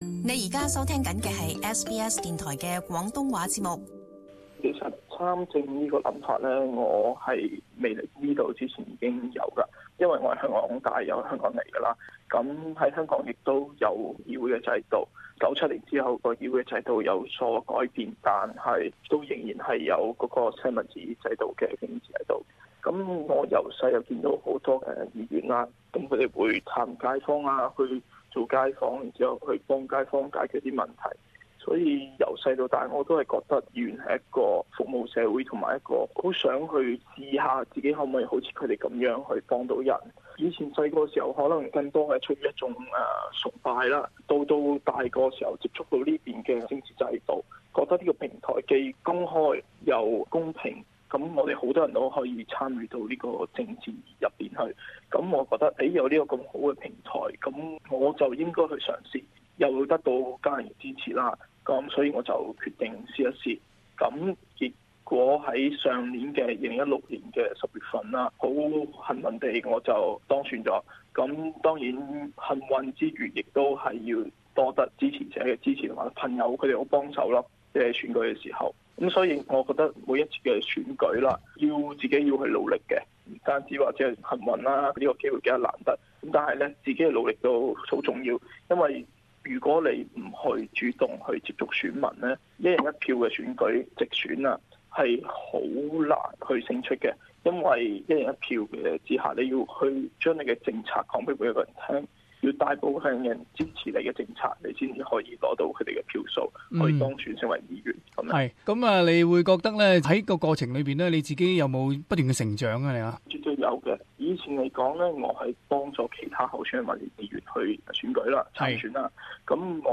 【社團專訪】訪問墨爾本蒙納殊市政府議員蔡鵬的從政意願